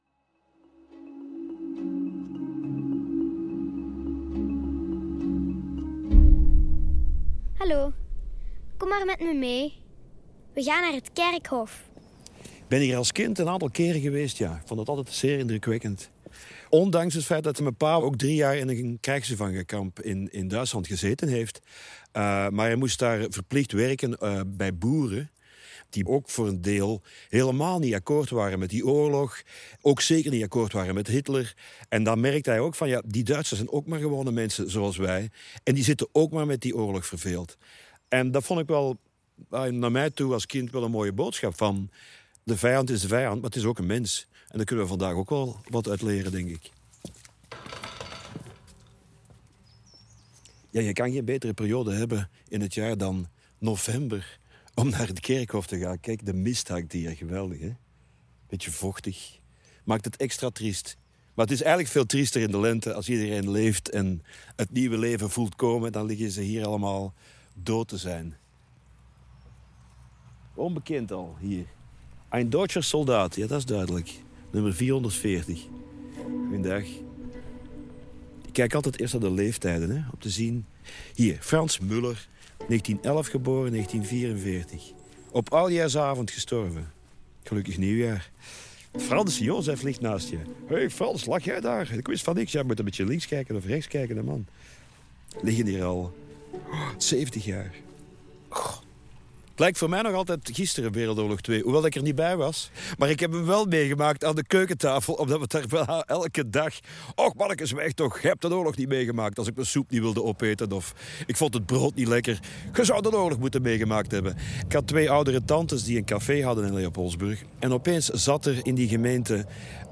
radioreeks
2016 Duitse militaire begraafplaats Lommel (BE)